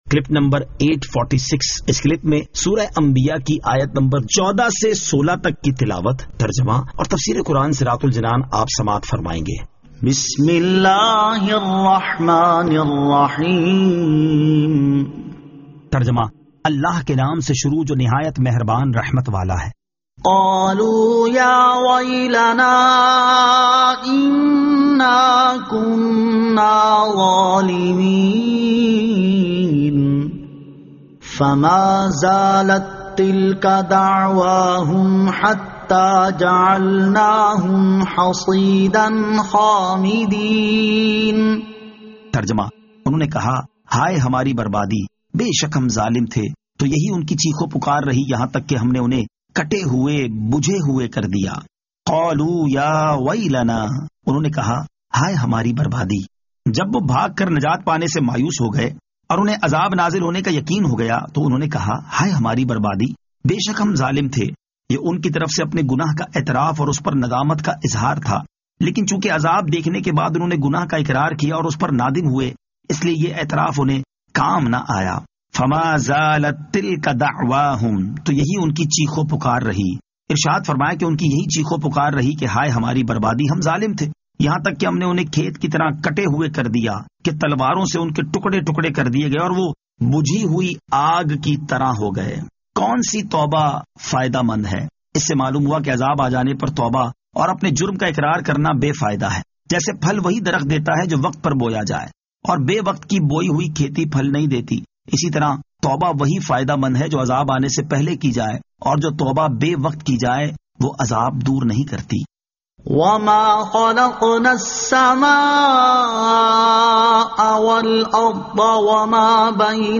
Surah Al-Anbiya 14 To 16 Tilawat , Tarjama , Tafseer